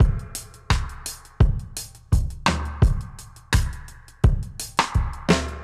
Index of /musicradar/dub-drums-samples/85bpm
Db_DrumsA_Wet_85_03.wav